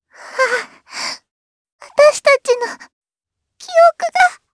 Lavril-Vox_Dead_jp.wav